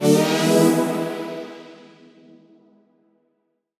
FR_ZString[up]-E.wav